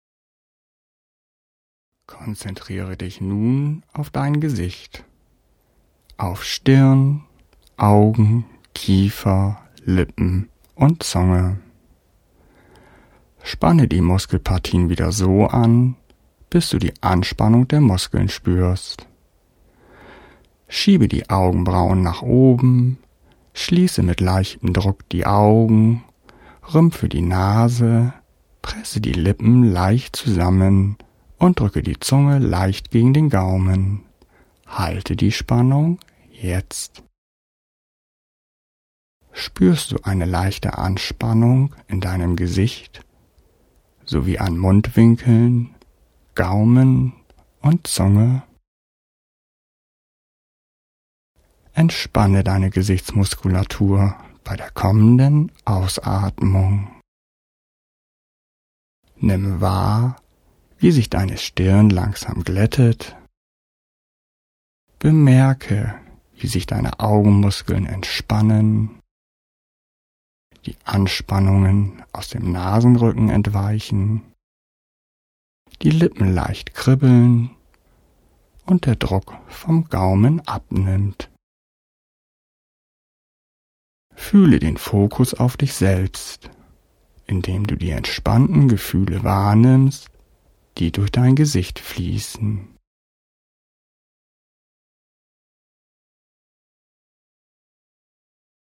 Progressive Muskelentspannung im Sitzen ohne Melodie
Progressive Muskelentspannung mit 4 Muskelgruppen sitzend ohne Musik nach Jacobson zum Download
Hörprobe der Variante mit 4 Muskelgruppen im Sitzen oder auf